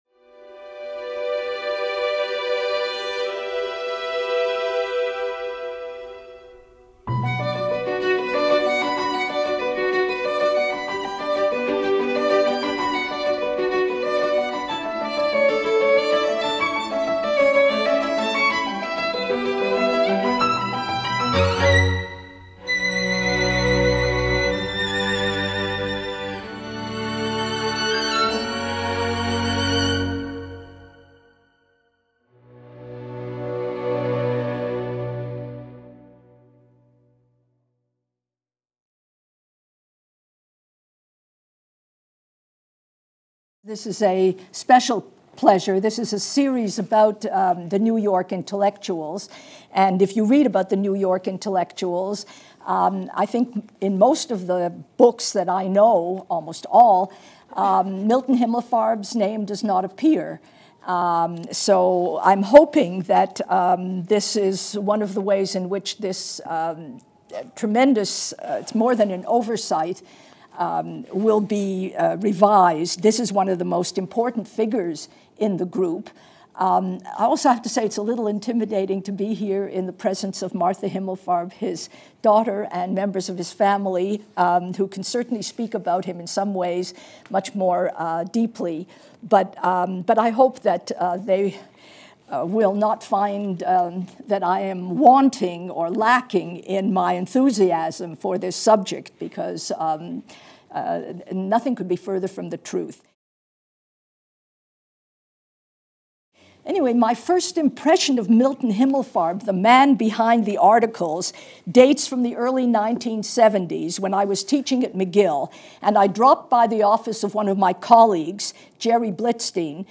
Milton Himmelfarb stood out from the other New York Intellectuals of his era for one reason: he was primarily interested in the Jews and Jewish issues. As Professor Wisse explains in this lecture, Him...